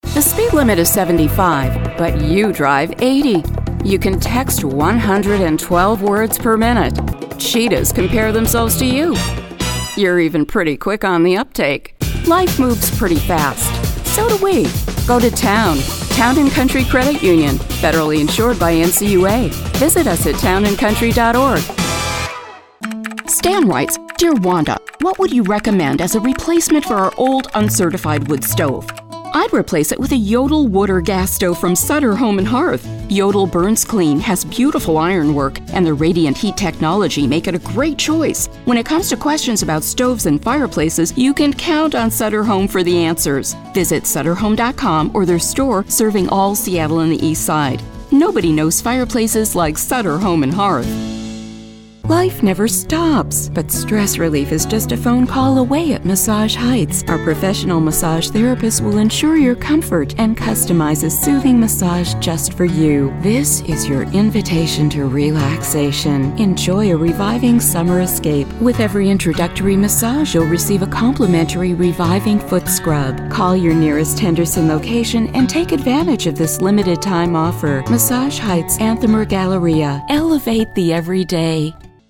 Female
Adult (30-50), Older Sound (50+)
A believable, versatile, mature voice that brings copy to life: the mom or neighbor next door, the business professional, doctor or nurse, sultry siren or sophisticated lady, announcer or storyteller...classy to sassy, honest and trustworthy, warm and friendly, caring and compassionate.
Radio Commercials
96104Radio.mp3